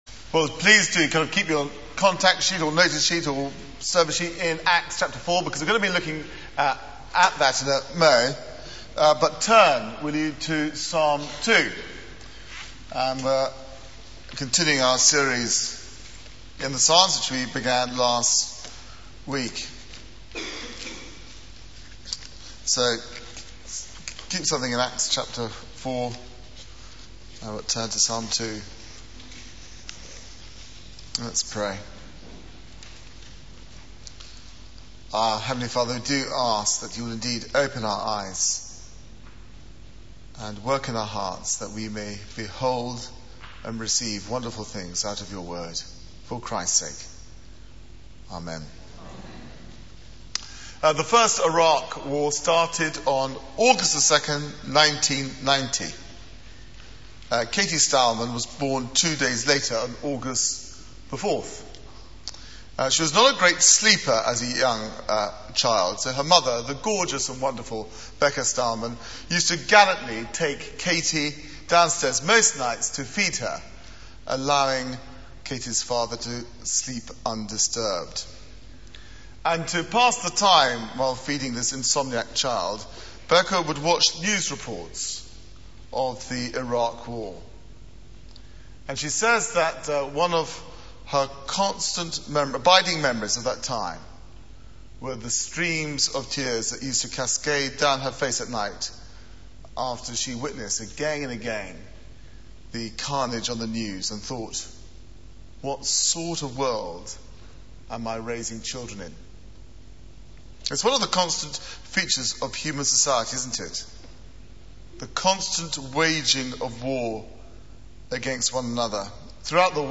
Media for 9:15am Service on Sun 21st Jun 2009 18:30 Speaker: Passage: Psalm 2 Series: Summer Songs Theme: How to make God Laugh Sermon Search the media library There are recordings here going back several years.